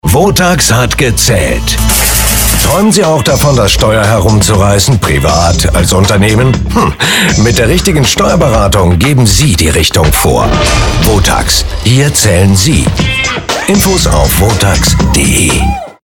Wir freuen uns daher sehr, Sie auf die neuen WOTAX-Radiospots aufmerksam machen zu dürfen, die derzeit auf „100,5 Das Hitradio“ zu hören sind.
Radiospot WOTAX Image
4_WOTAX-Image-16-Sekunden.mp3